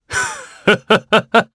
Roman-Vox_Happy1_jp.wav